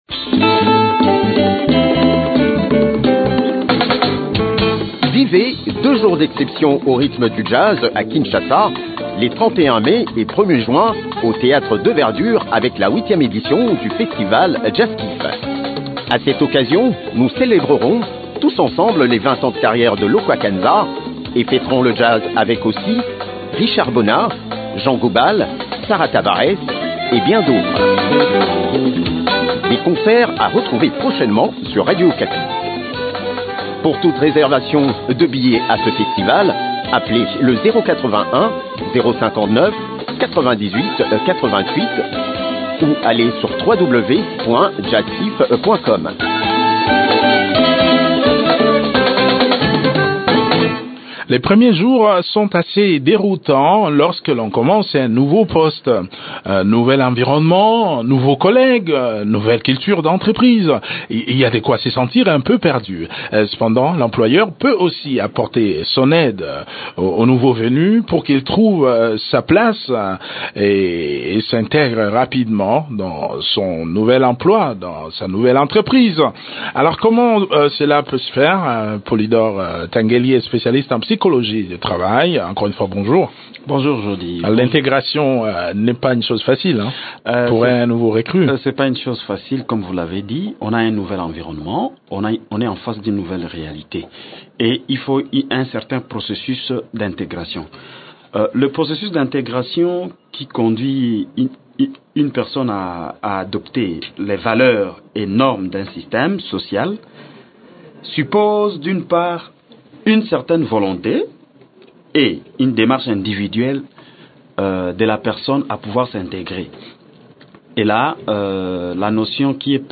expert en psychologie du travail.